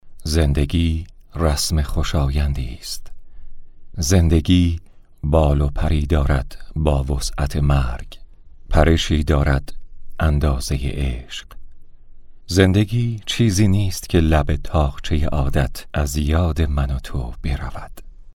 shaerane.mp3